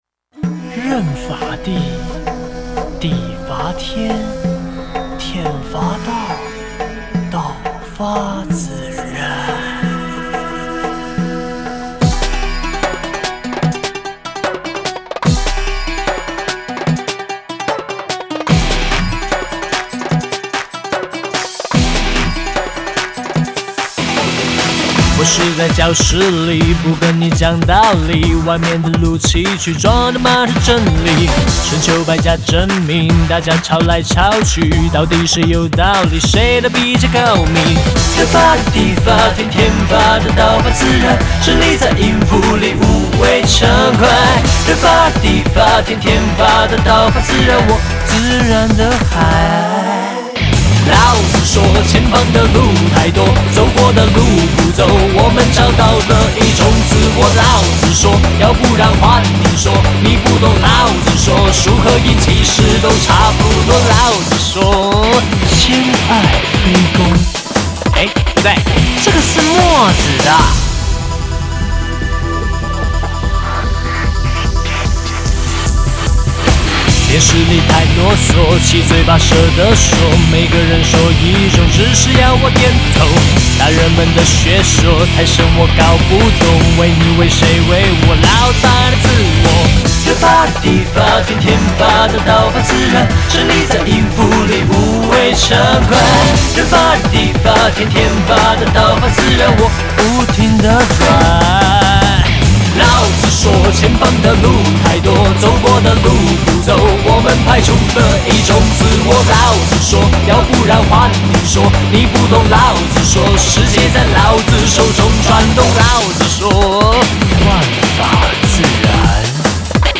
电子摇滚新哲学EP
曲风更是有别以往，首次尝试电子的曲风。
新式电音免摇头 电子摇滚闪到腰